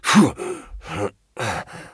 pain_13.ogg